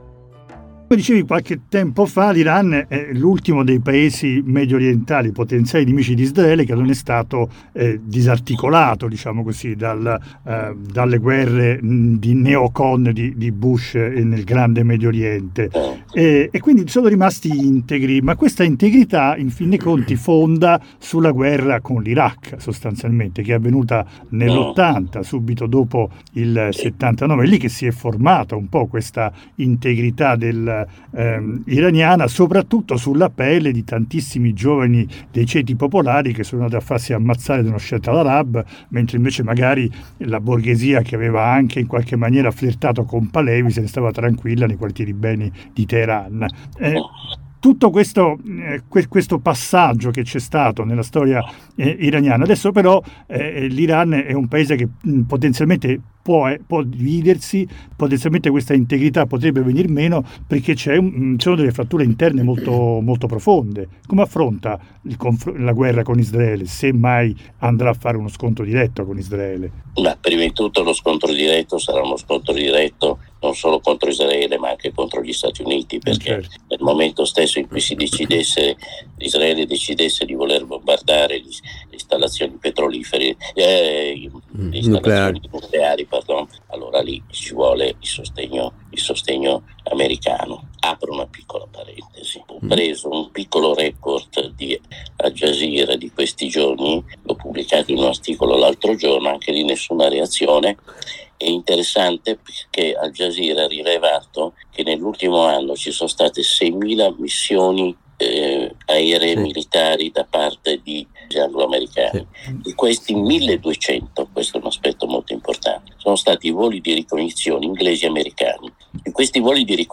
Nella seconda parte della nostra lunga conversazione con Alberto Negri parliamo dell'Iran che il nostro interlocutore conosce bene.